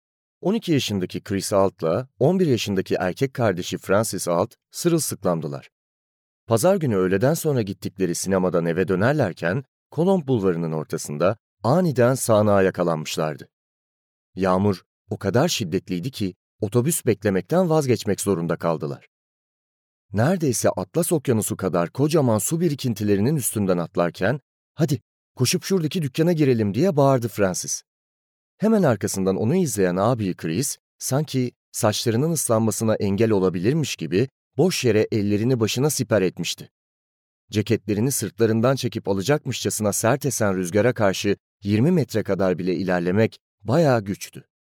Sesli Kitap
Deneyimli seslendirme sanatçılarının okuduğu, editörlüğümüz tarafından özenle denetlenen sesli kitap koleksiyonumuzun ilk örneklerini paylaşmaktan sevinç duyuyoruz.